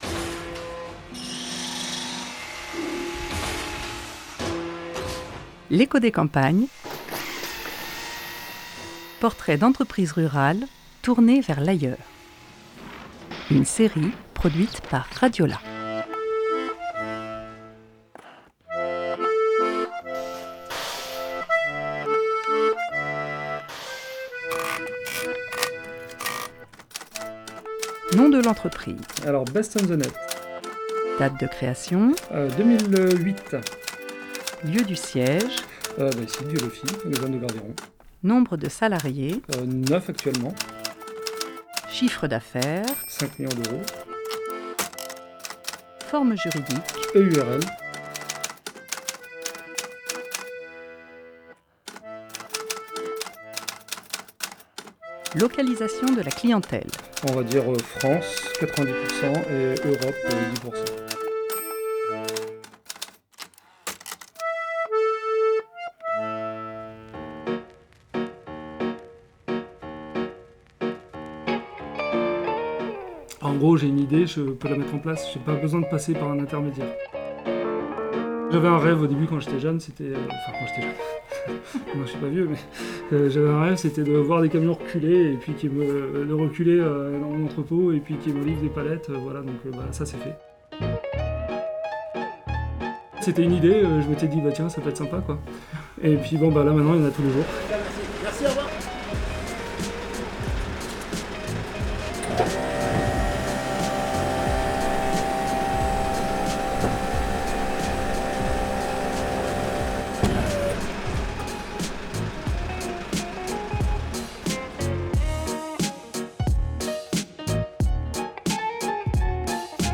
9 juin 2022 16:07 | eco des campagnes, podcasts maison, reportage, territoire